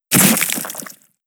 Head Explode Sound.wav